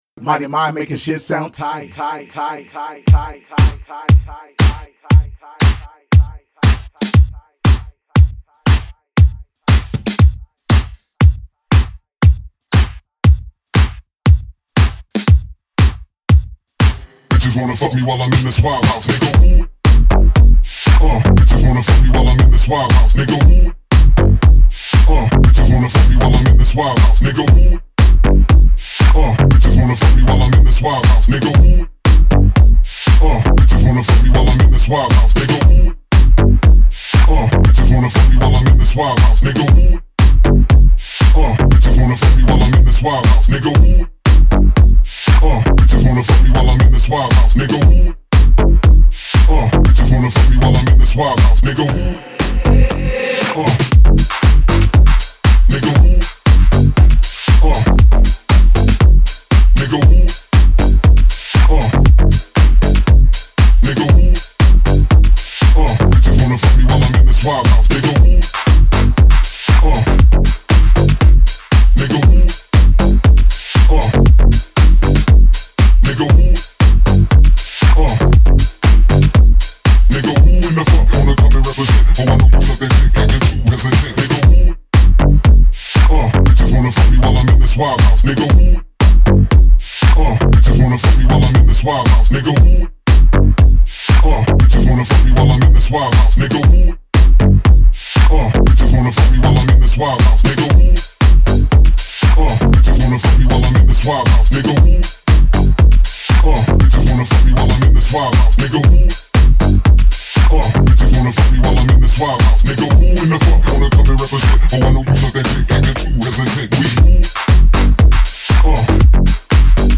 He describes his sound as 80% uptempo and 20% hip hop.